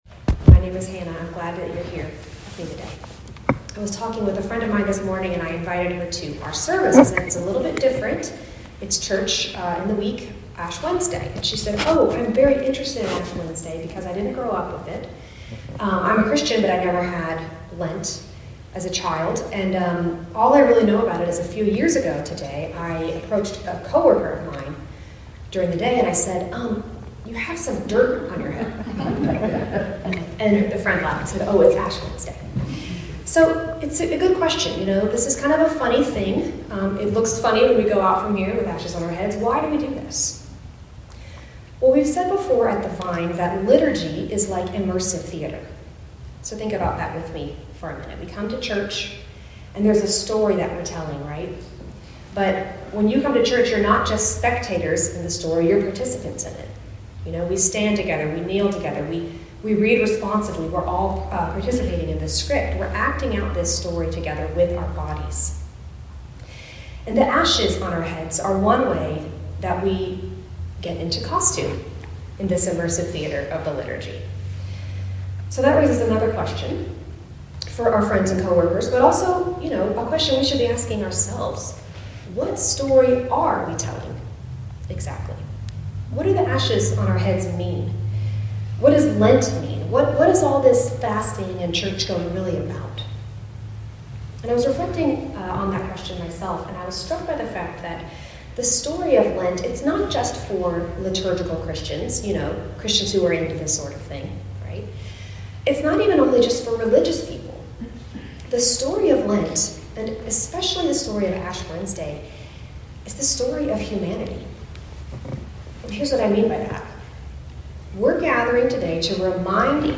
Humanity's Story (Ash Wednesday Service)